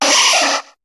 Cri de Nidorina dans Pokémon HOME.